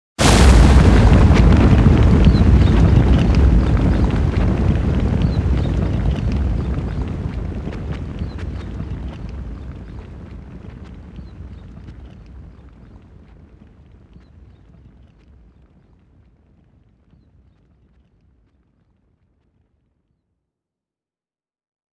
earthquake_hit.wav